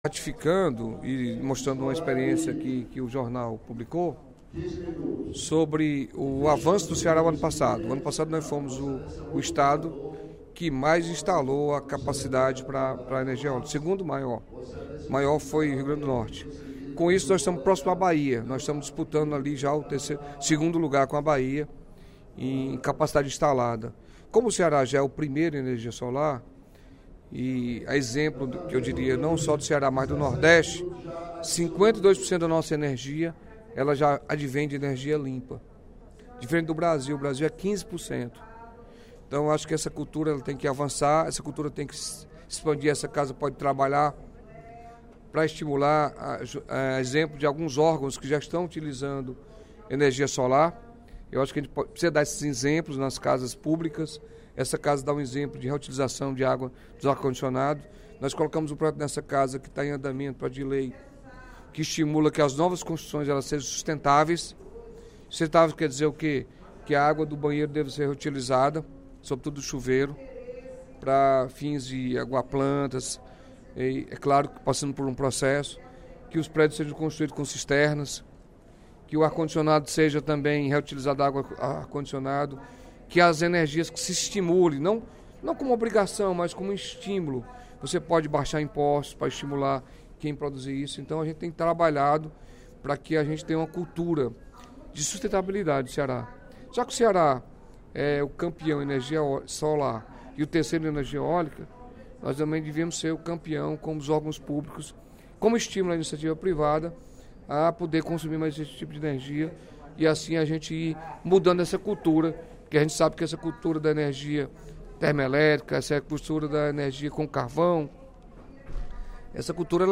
O deputado Carlos Felipe (PCdoB) ressaltou, durante o primeiro expediente da sessão plenária desta sexta-feira (05/05), o fato de o Ceará conseguir, no ano de 2016, aumentar a produção de energia eólica, adicionando dez vezes mais energia à matriz do Estado.
Em aparte, o deputado Dr. Santana (PT) exaltou a importância de se valorizar a produção de energia eólica.